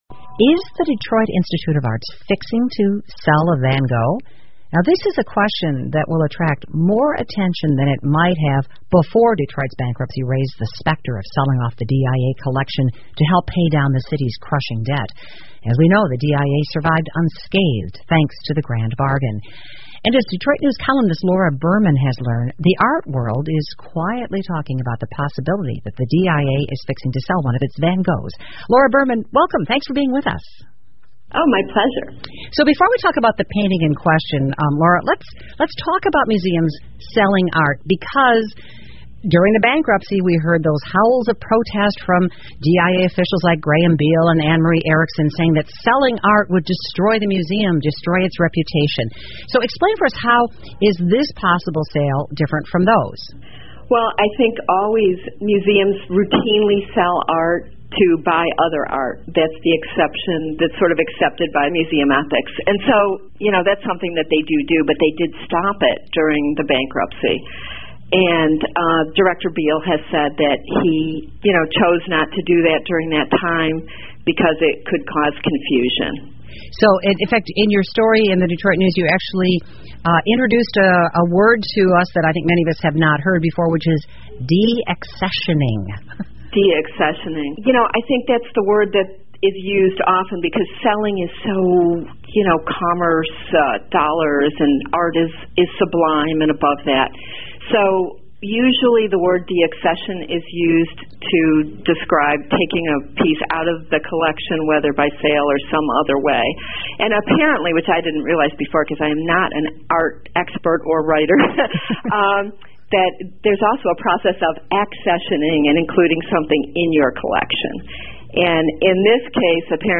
密歇根新闻广播 DIA真得要卖掉梵高的画吗? 听力文件下载—在线英语听力室